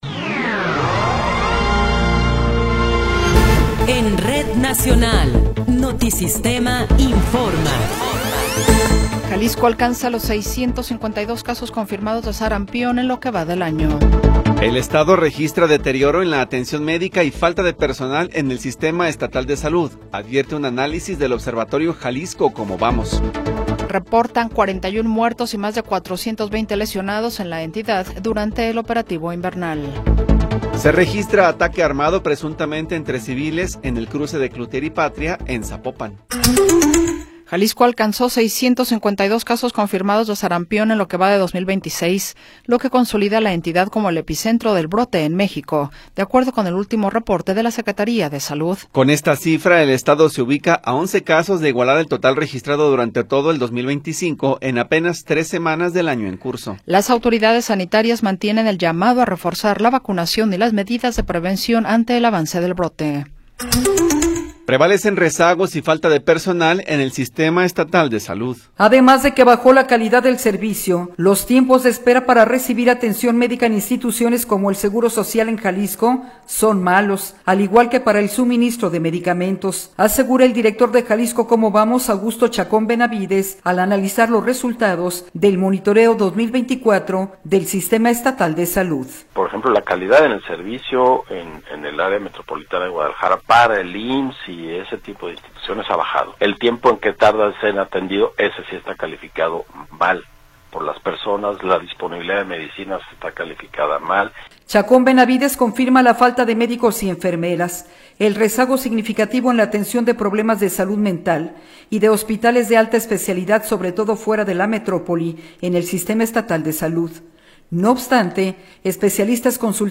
Noticiero 14 hrs. – 27 de Enero de 2026